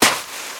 High Quality Footsteps
STEPS Sand, Walk 02.wav